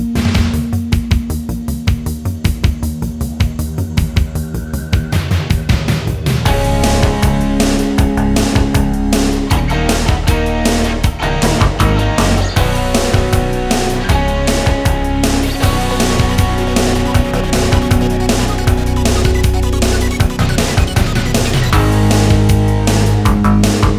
no Backing Vocals Soundtracks 3:32 Buy £1.50